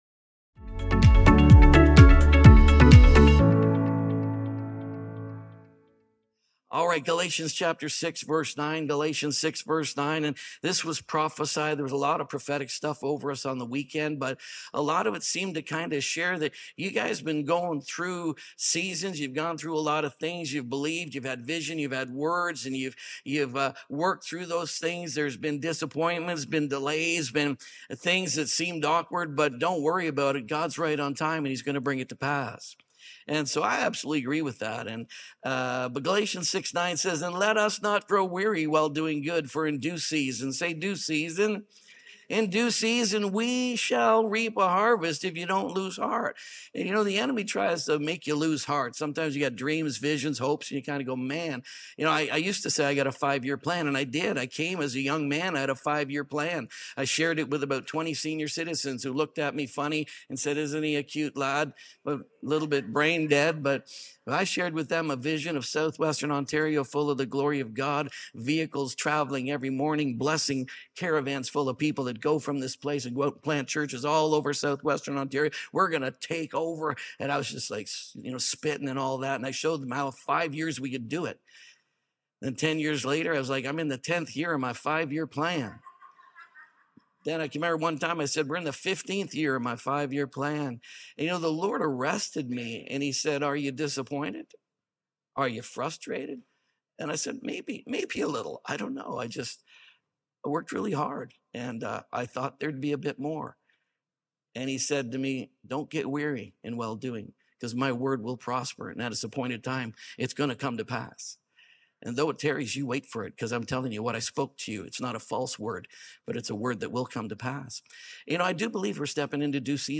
41 to 50 | ROAR Conference | Sermon Only.mp3